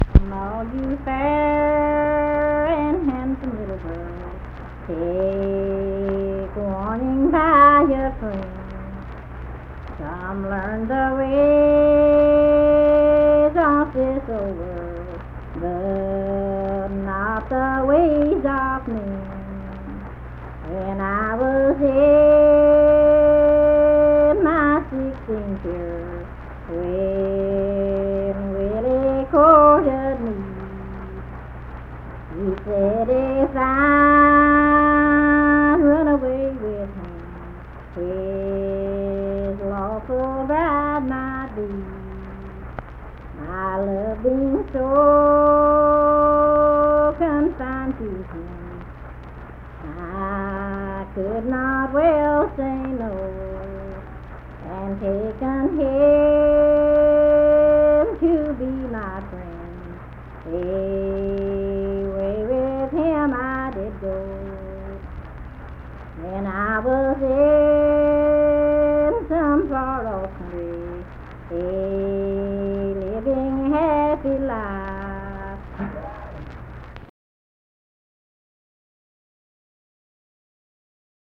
Unaccompanied vocal music
Verse-refrain 9(4).
Voice (sung)
Harts (W. Va.), Lincoln County (W. Va.)